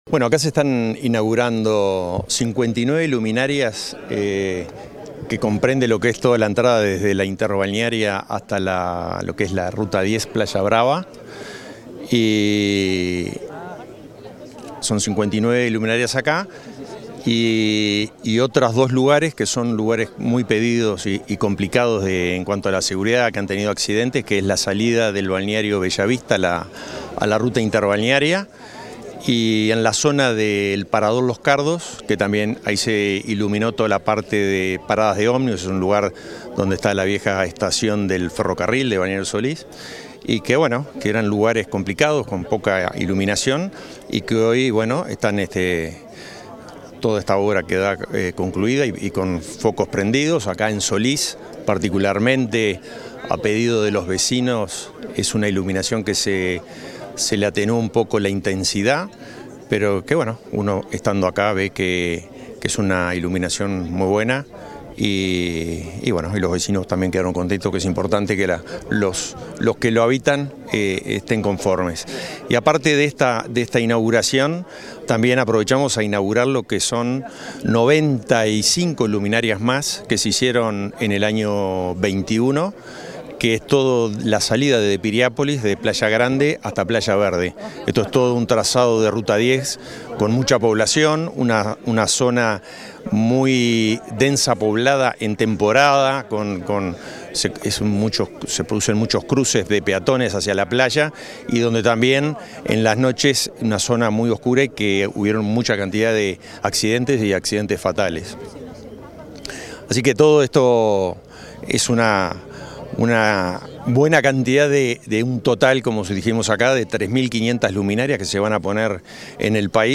Declaraciones a la prensa del director nacional de Vialidad, Hernán Ciganda
Tras el evento, el director de Vialidad, Hernán Ciganda, realizó declaraciones a la prensa.